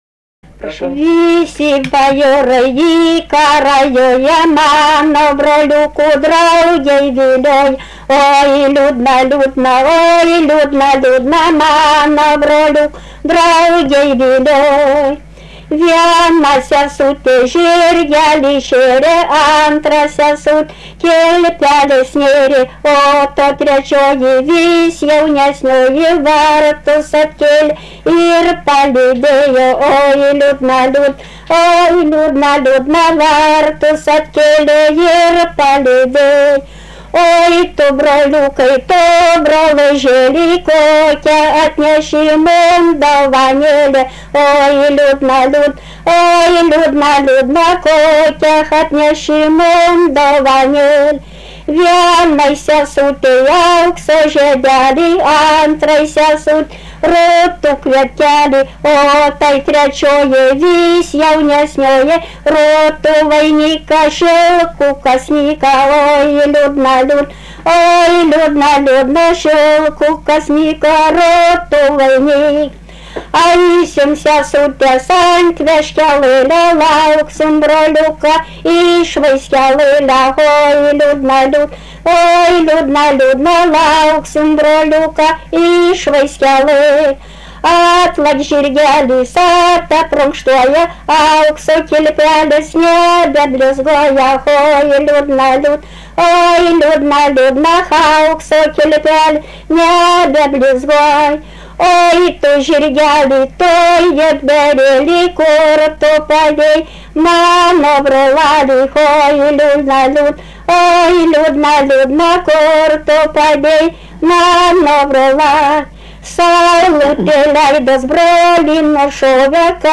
Vabalninkas
vokalinis